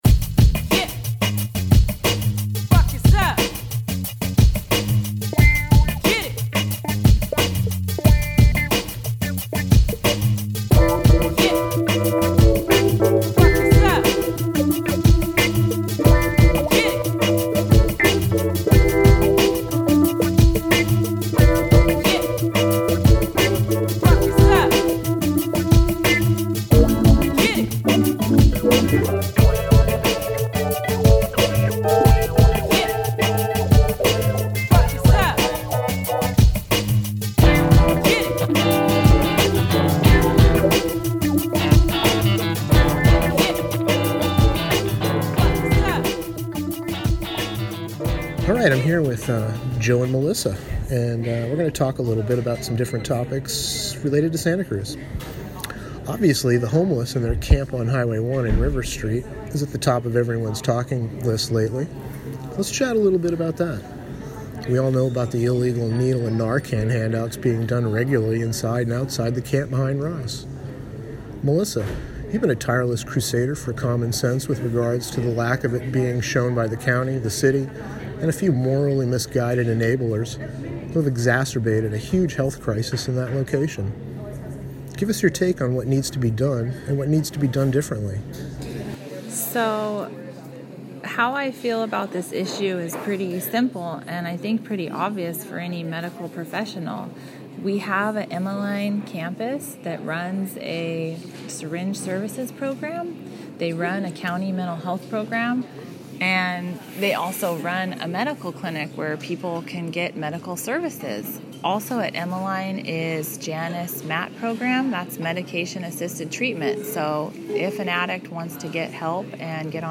A panel led discussion, a couple of regular recurring guests, and we talk about local topics and issues relat…